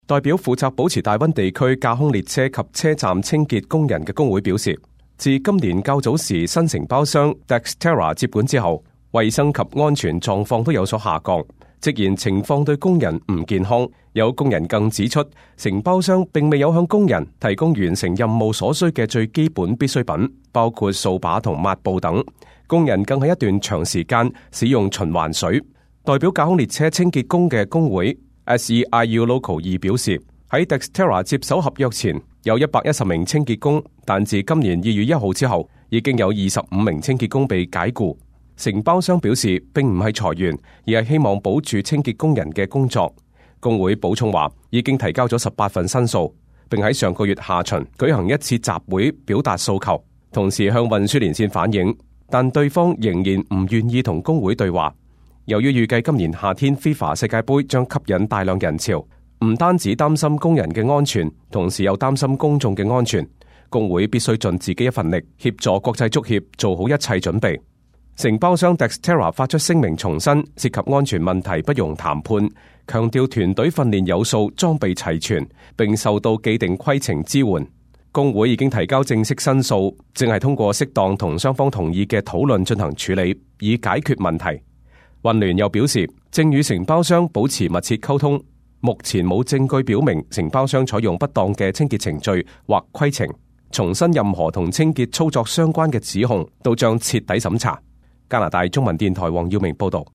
Local News 本地新聞